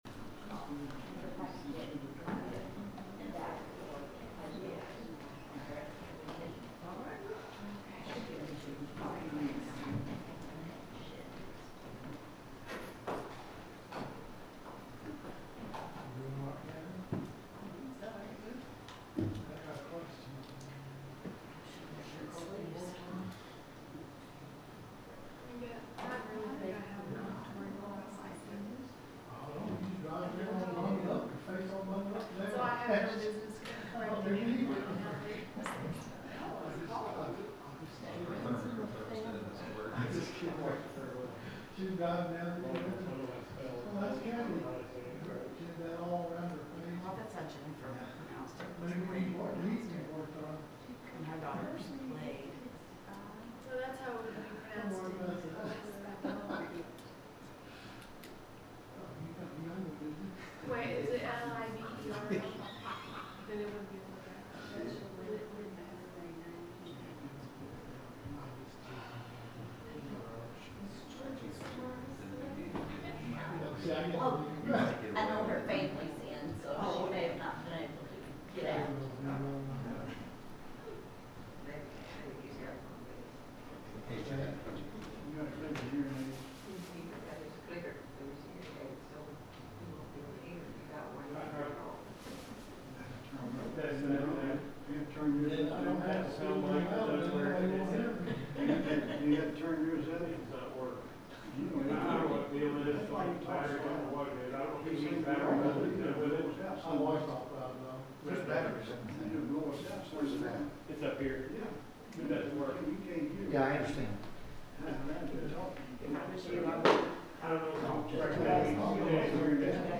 The sermon is from our live stream on 11/26/2025